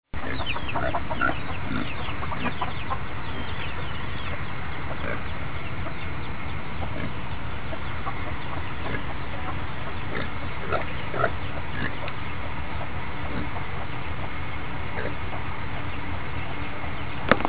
Pig section (For background sound click picture)